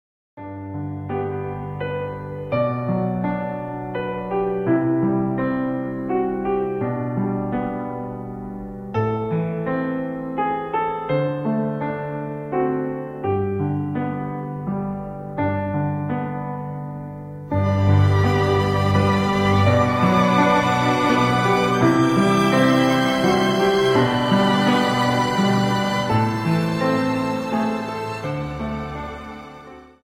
Slow Waltz 28 Song